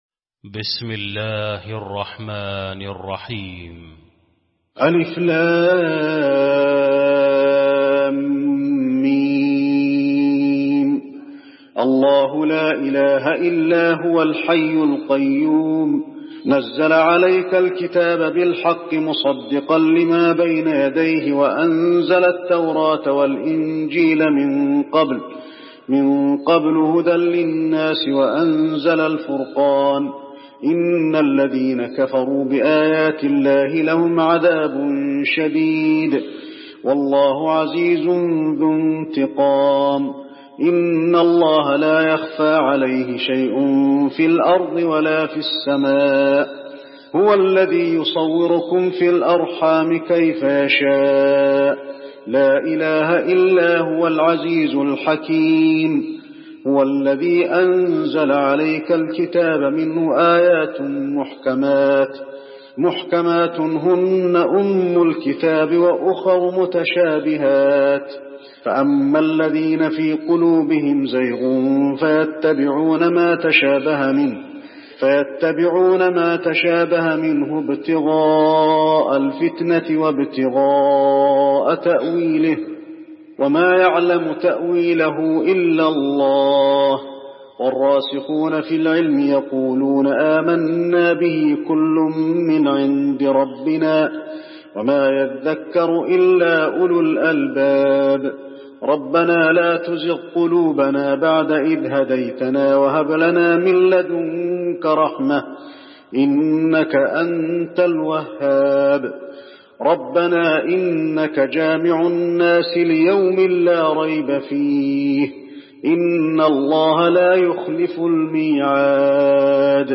المكان: المسجد النبوي آل عمران